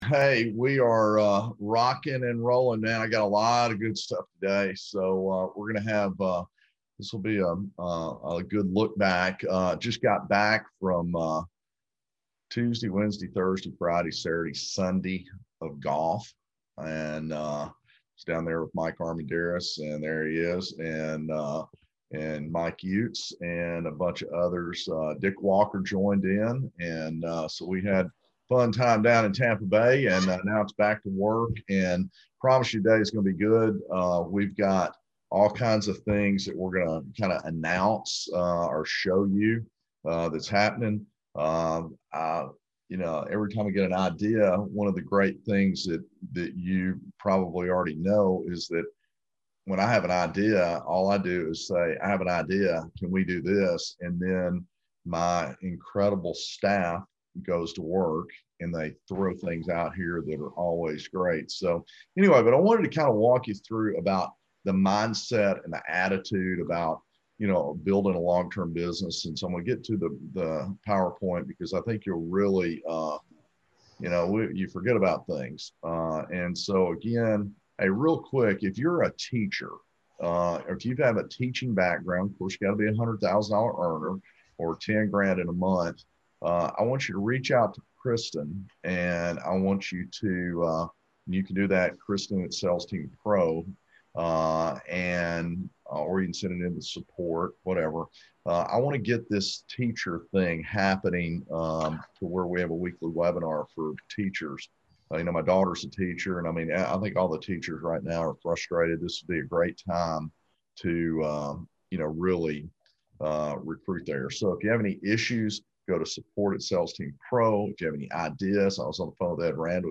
Tuesday Webinar March 30 2021